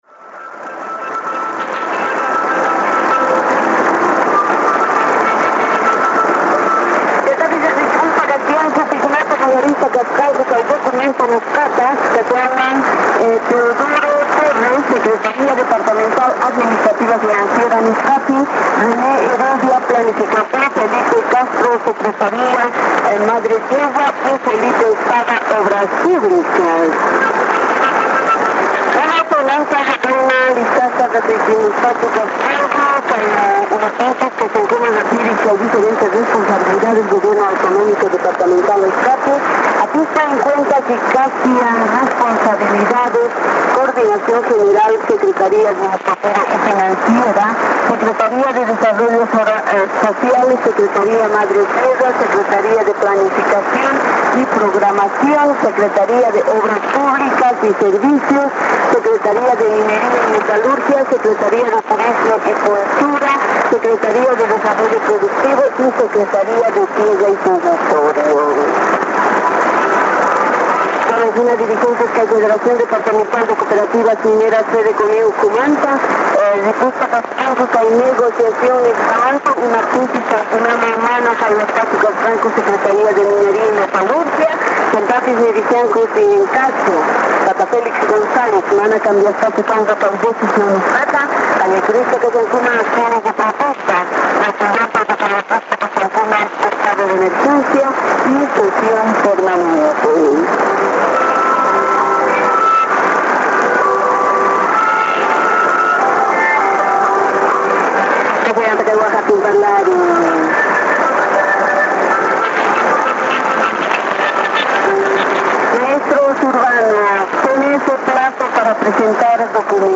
Trechos de áudios de captações realizadas durante o DXCamp Lorena 2010 ocorrido durante os dias 3 e 6 de junho de 2010.
As captações foram realizadas pelos participantes do encontro, utilizando os mais variados equipamentos e antenas.